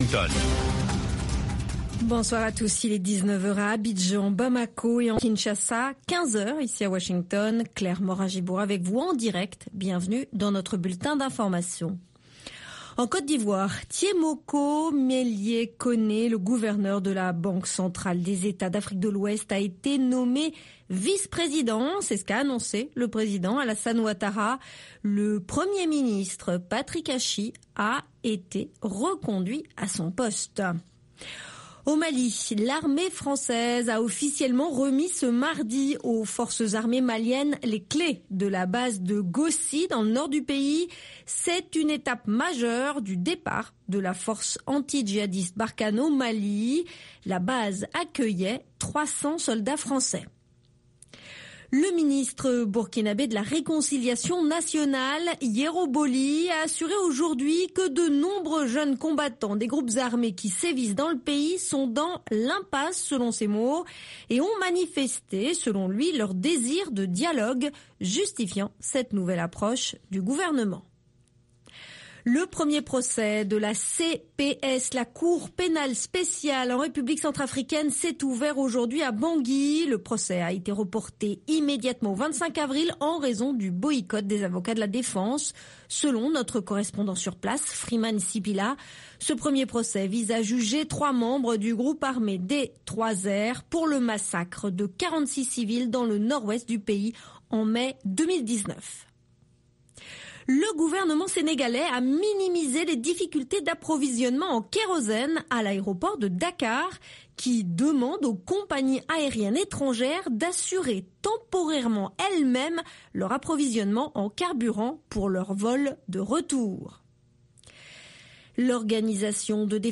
3 min Newscast